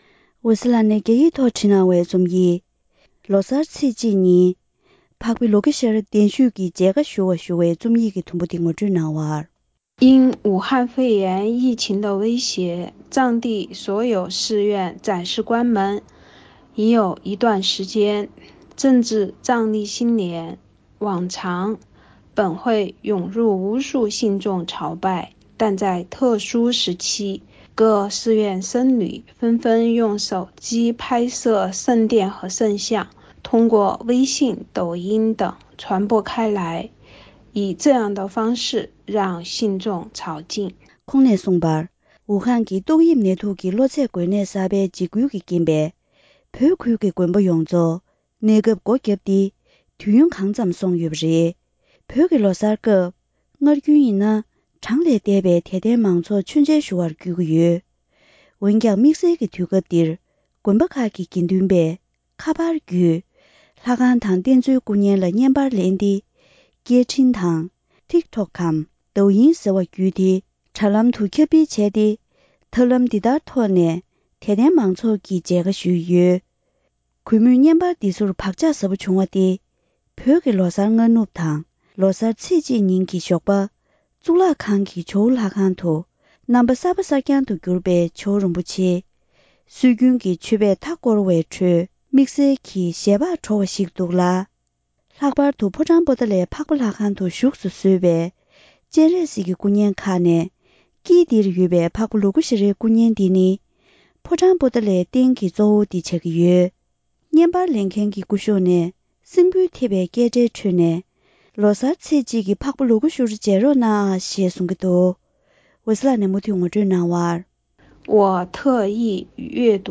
ཕབ་བསྒྱུར་དང་སྙན་སྒྲོན་ཞུས་པར་གསན་རོགས་ཞུ།།